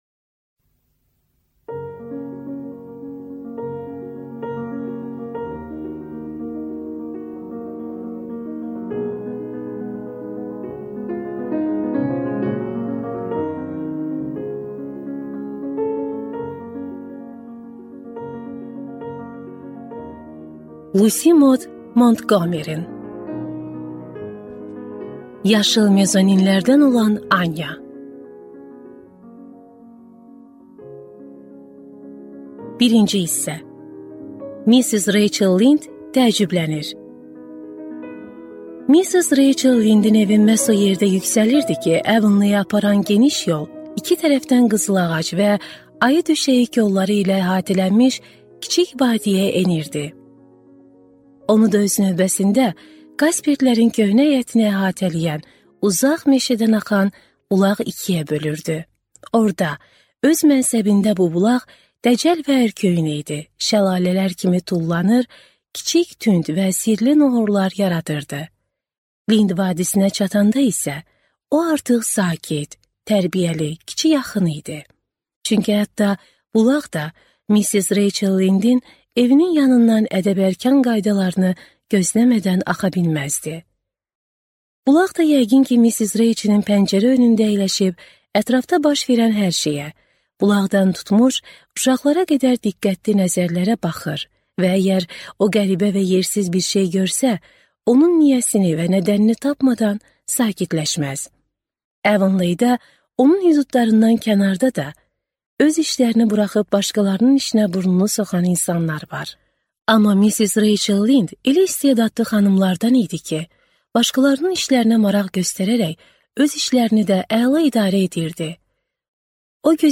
Аудиокнига Yaşıl mezoninlərdən olan Anya | Библиотека аудиокниг